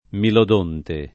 milodonte